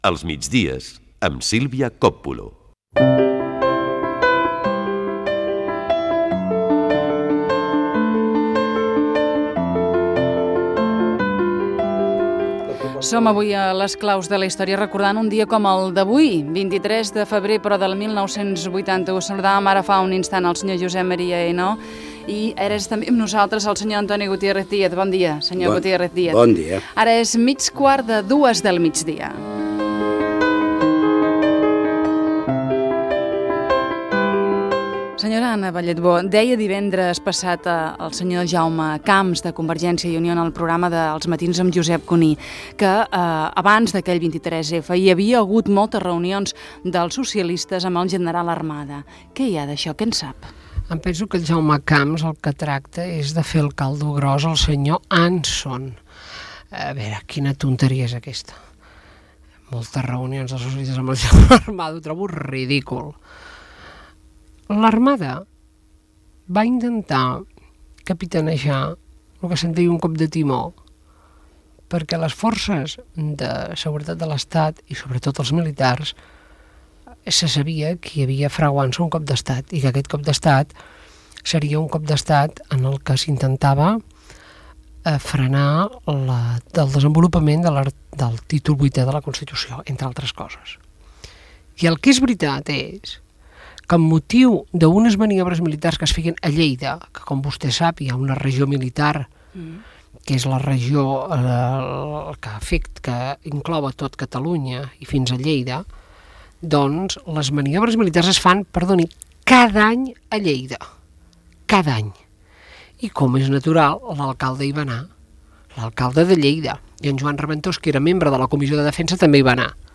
Indicatiu del programa, fragment d'una entrevista a la periodista i política Anna Balletbó sobre l'intent de cop d'estat del 23 de febrer de l'any 1981.
Entreteniment